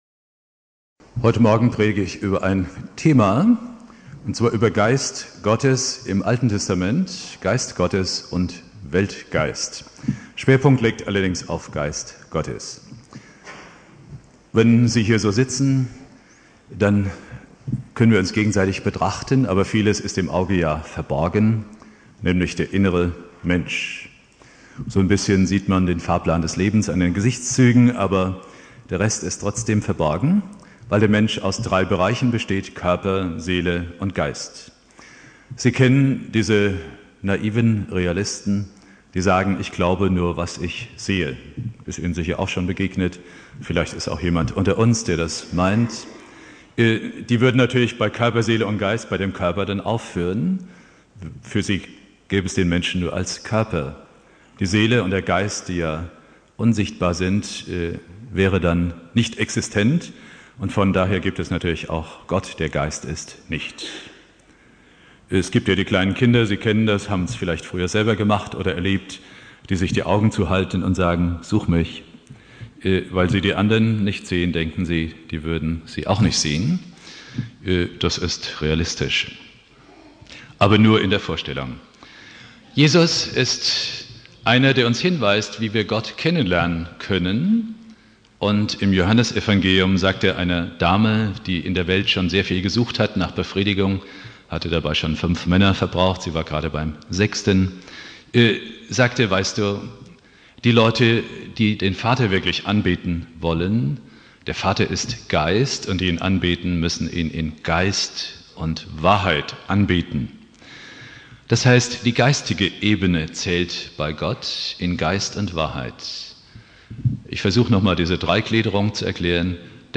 Predigt
Pfingstsonntag